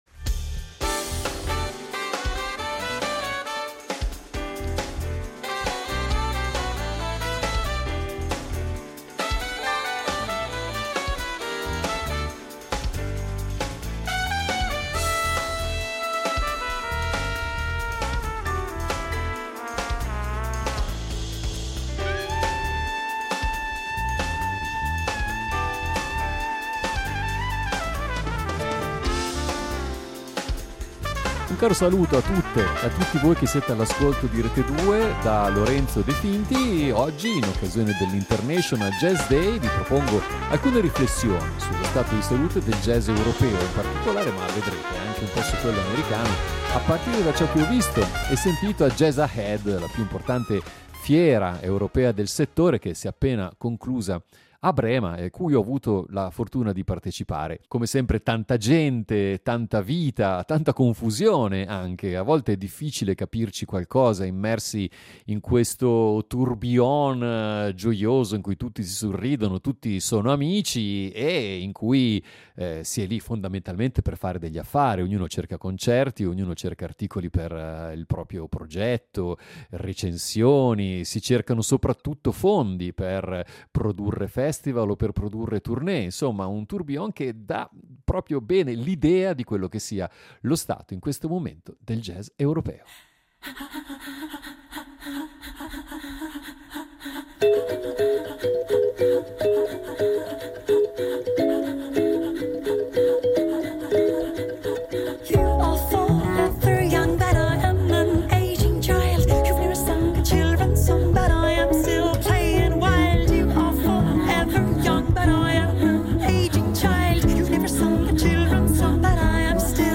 Ecco allora qualche riflessione – tra musica e parole – su ciò che abbiamo visto, ascoltato e sentito vibrare in questi giorni intensi.